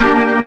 B3 GMIN 1.wav